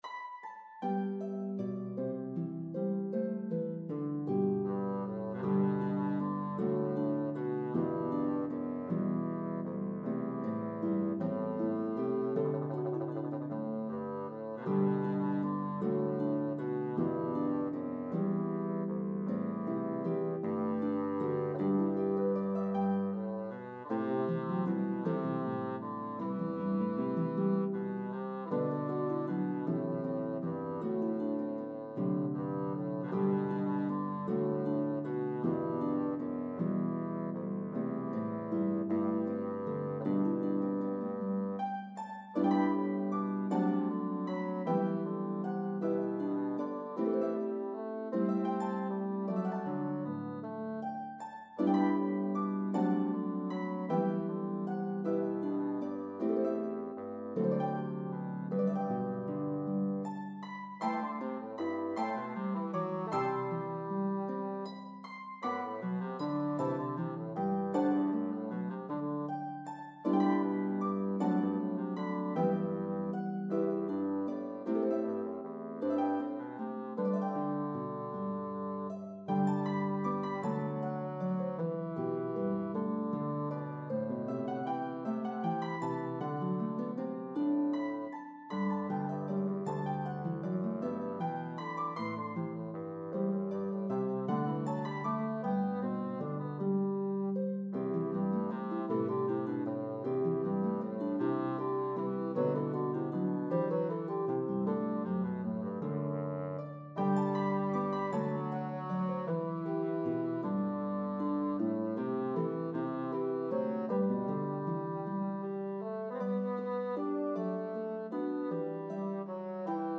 Harp and Bassoon version